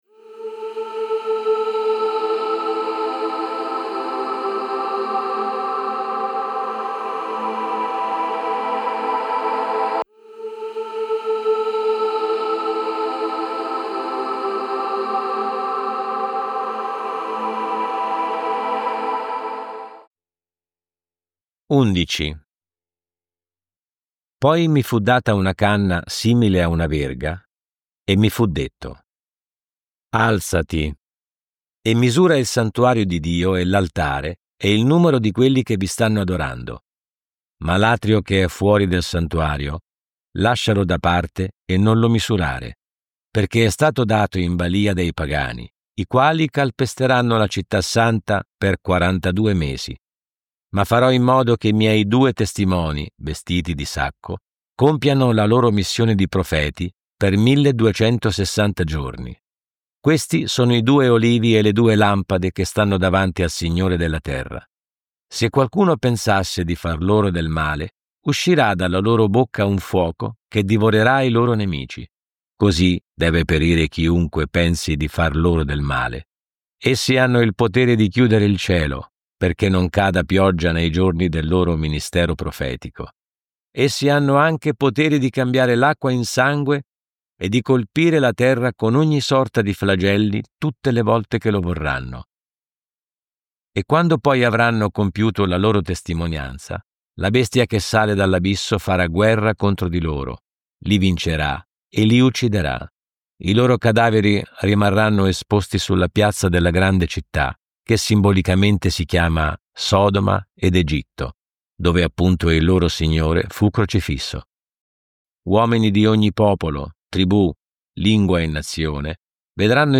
Audiolibri integrali, sempre gratis.